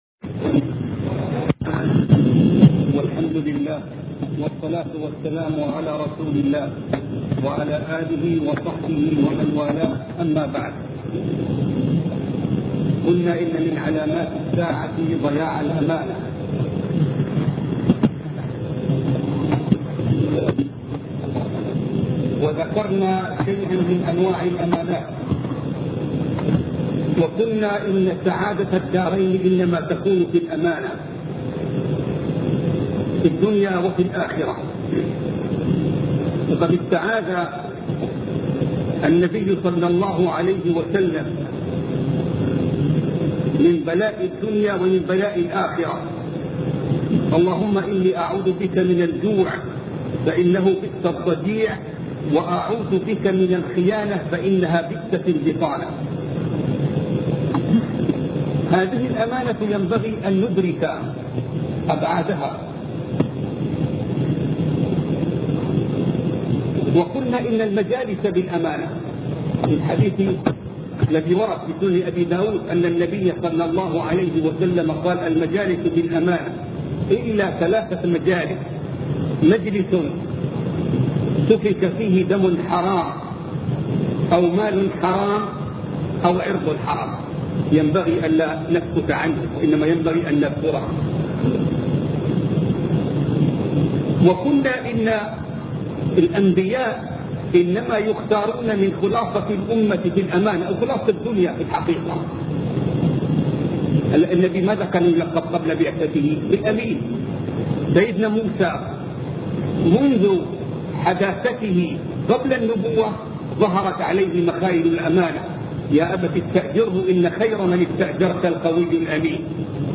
سلسلة محاضرات أشراط الساعة الوسطئ